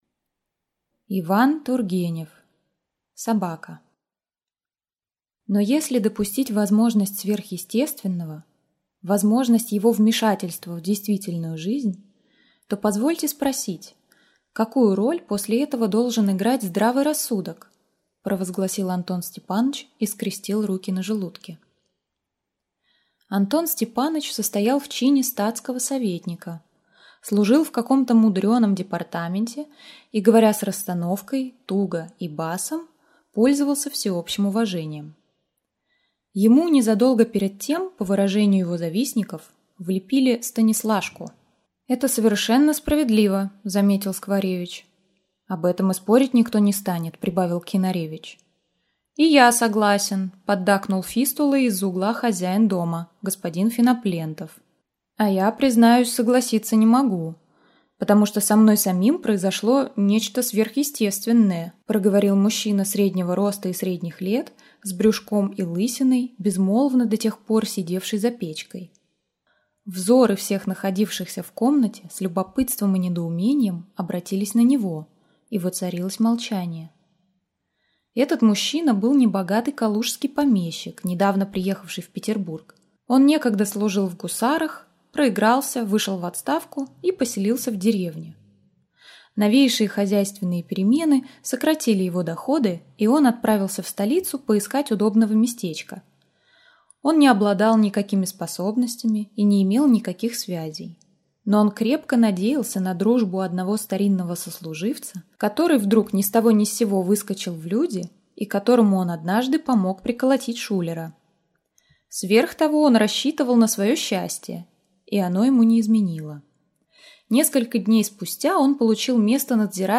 Аудиокнига Собака | Библиотека аудиокниг